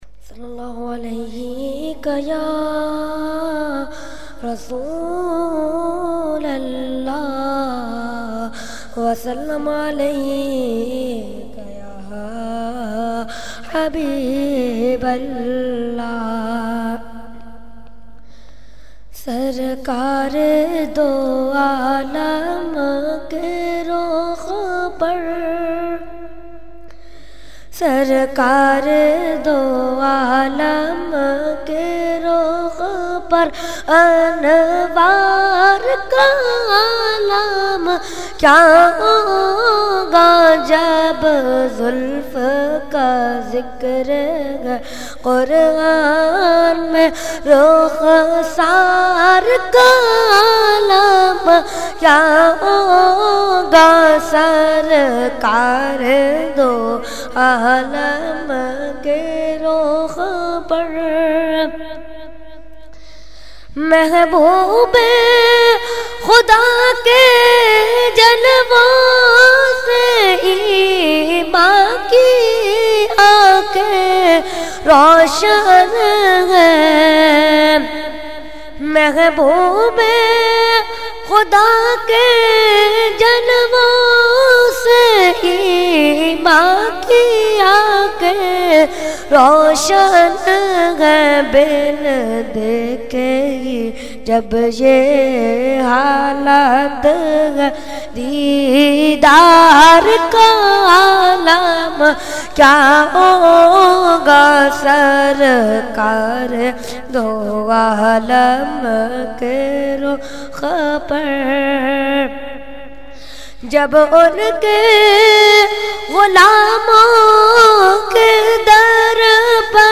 Category : Naat
Taziyati Program